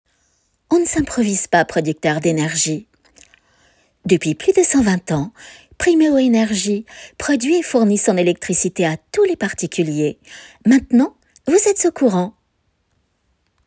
Test voix
Comédienne , voix off depuis 1983